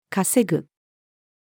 稼ぐ-female.mp3